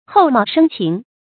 厚貌深情 注音： ㄏㄡˋ ㄇㄠˋ ㄕㄣ ㄑㄧㄥˊ 讀音讀法： 意思解釋： 外貌厚道，內心不可捉摸。